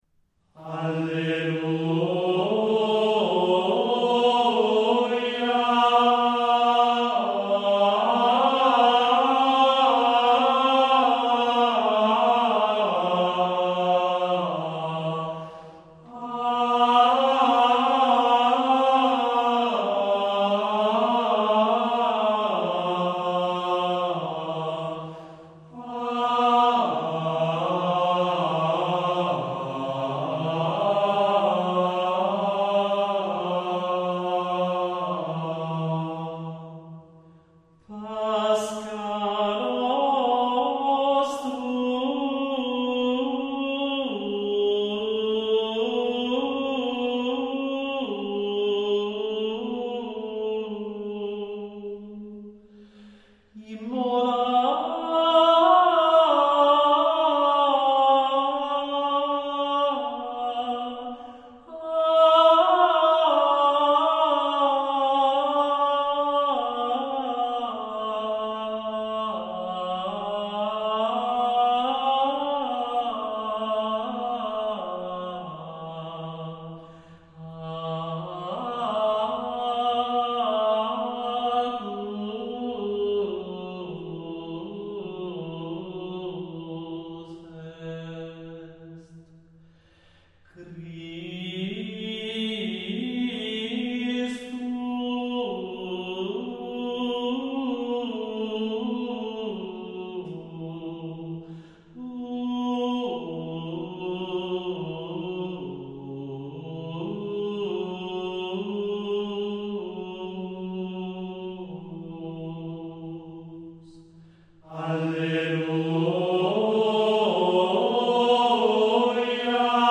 è in VII modo e si canta nella messa di Pasqua.